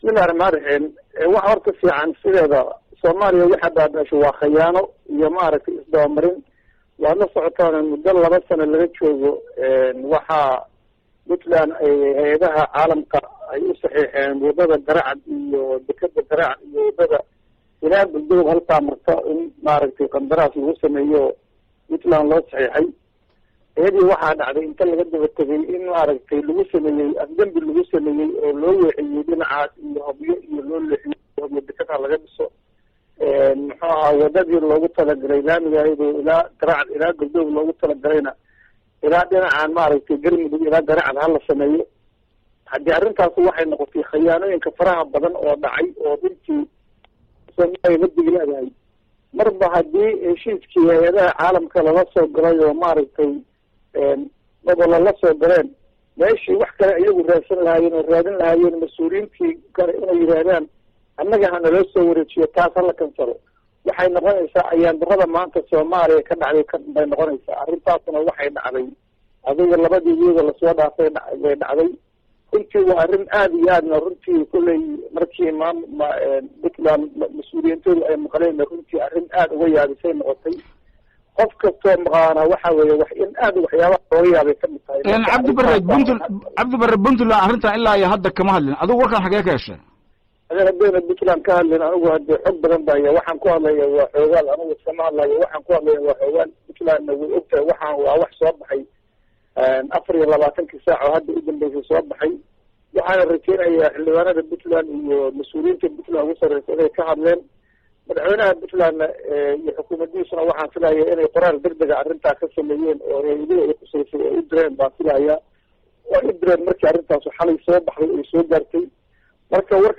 Dhageyso wareysiga Xildhibaan Cabdibarre uu Radio daljir siiyey